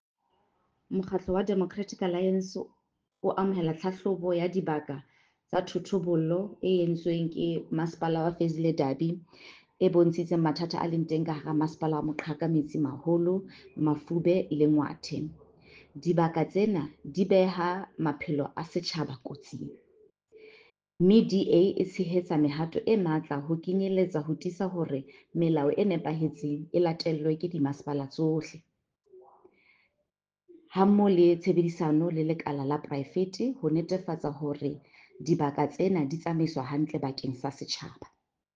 Sesotho soundbites by Cllr Mbali Mnaba.